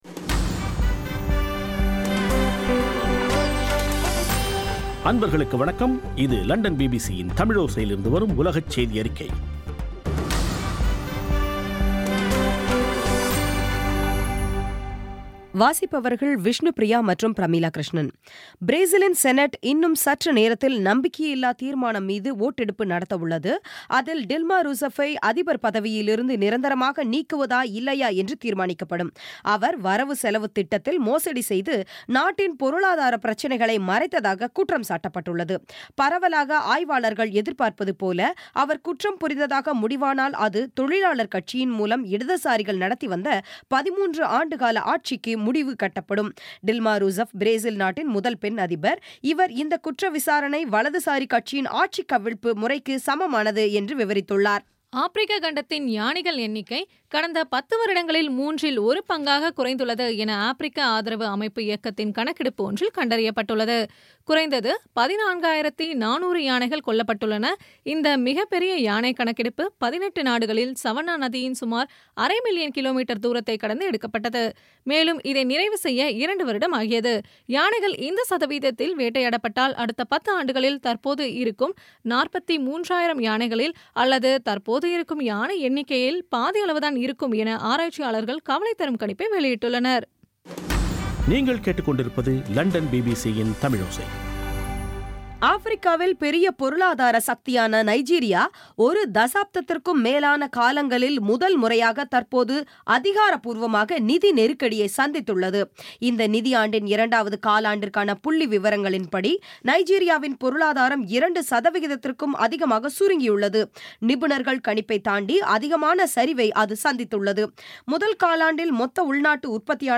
பி பி சி தமிழோசை செய்தியறிக்கை (31/08/2016)